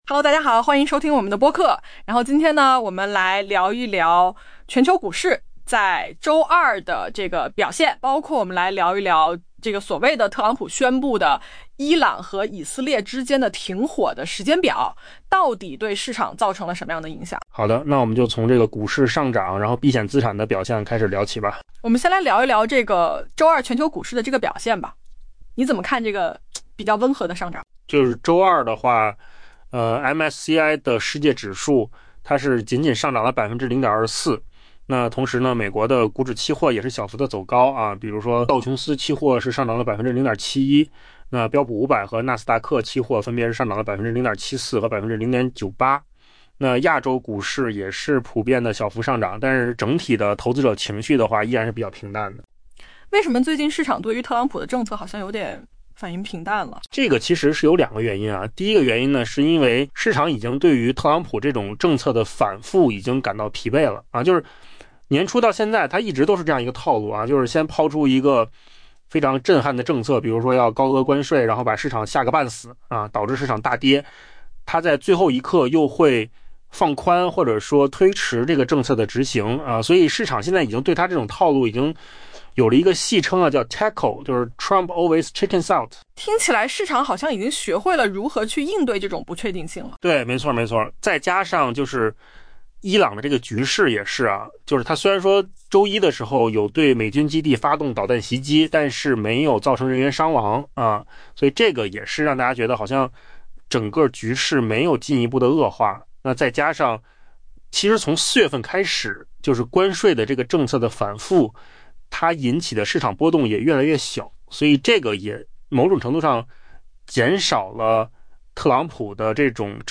AI播客：换个方式听新闻（音频由扣子空间生成） 下载mp3